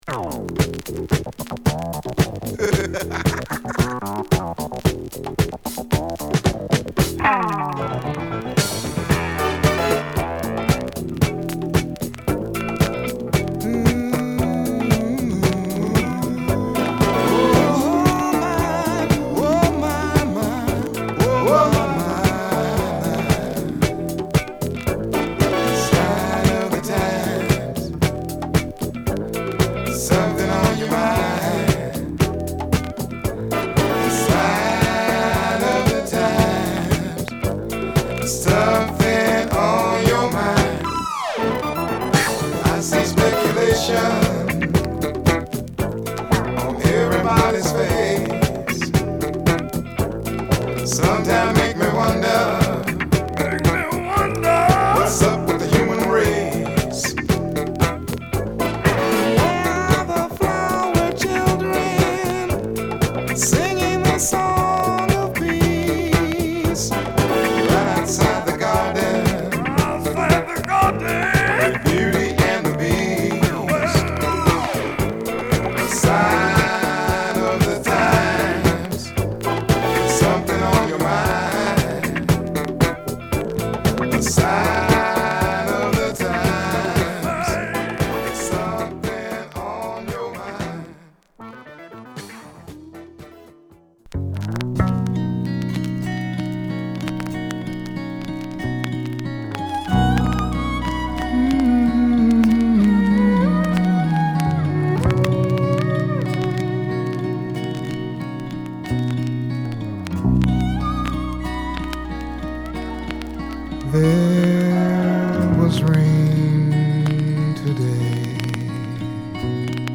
ジャズ／ソウルの絶妙なクロスオーヴァーをフォークギターを手に表現したシンガー・ソングライター
＊チリパチ出ます。